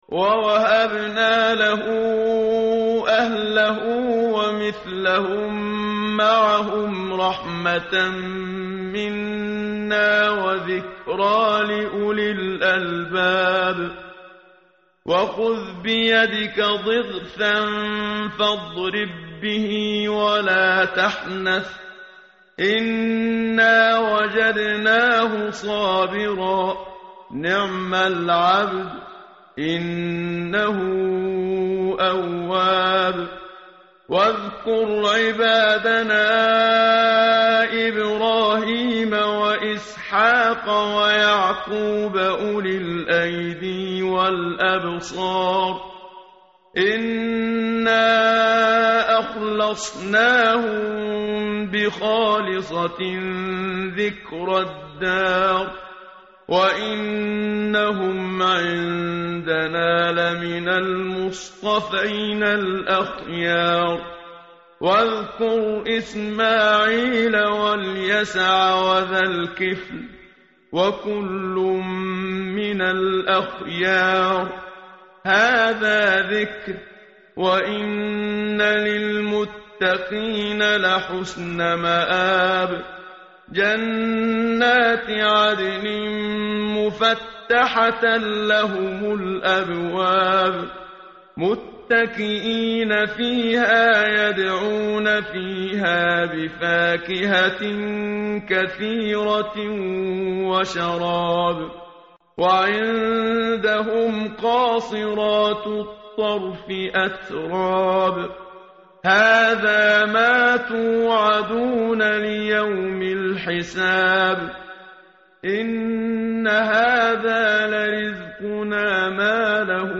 متن قرآن همراه باتلاوت قرآن و ترجمه
tartil_menshavi_page_456.mp3